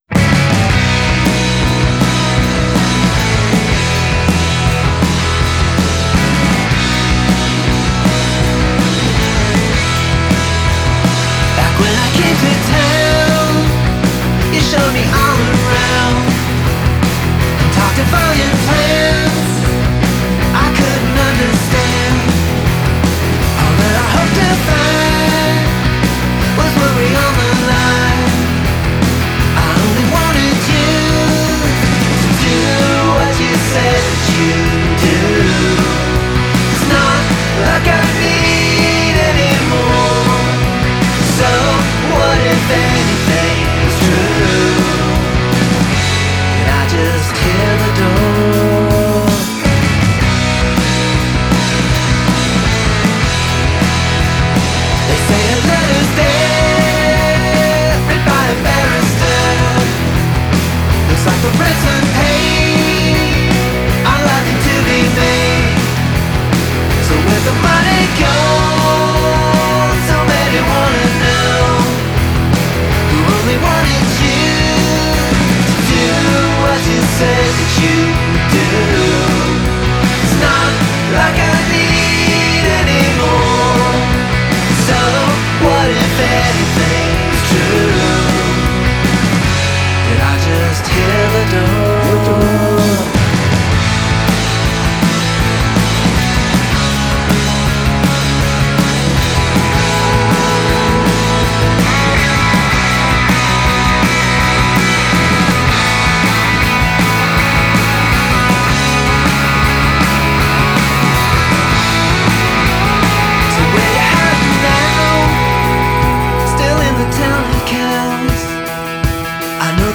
highly melodious and hooky